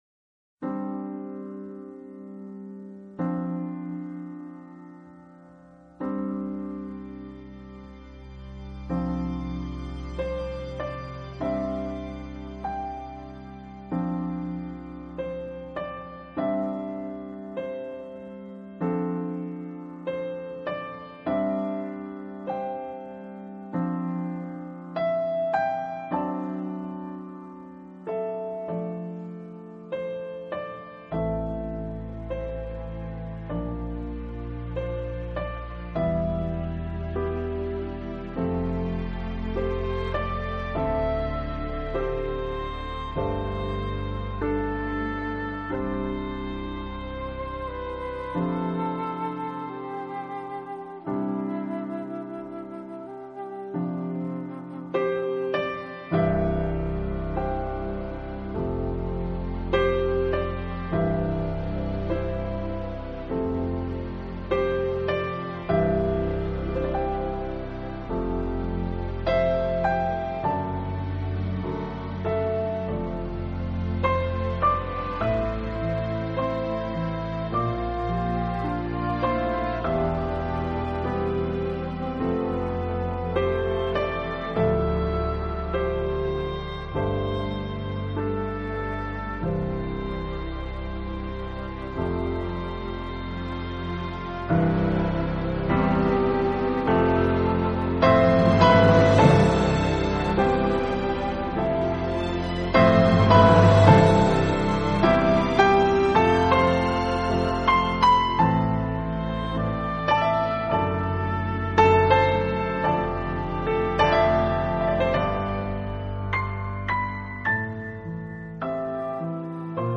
中的音乐演奏旋律相当着重延展性与留白。